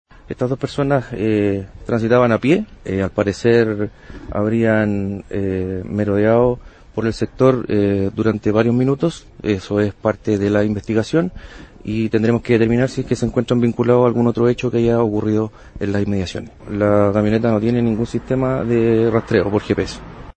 376-cuna-portonazo-pdi.mp3